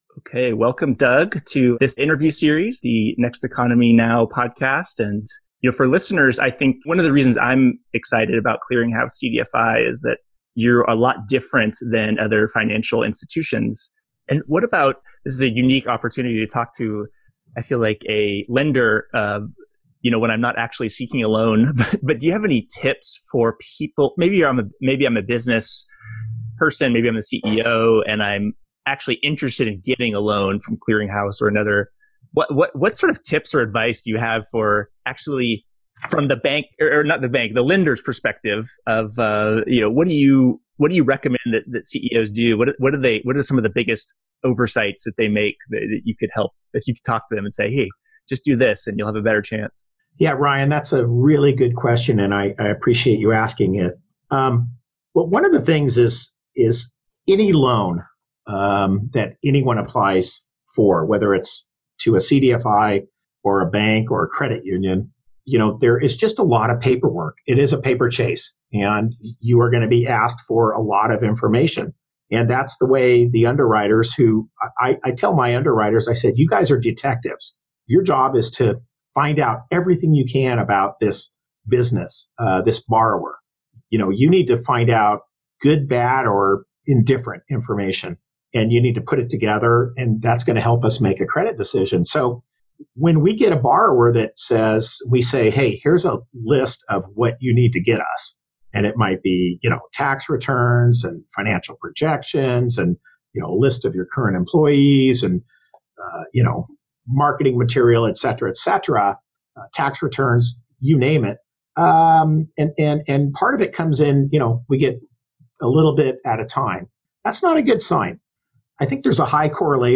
CCDFI Interview Series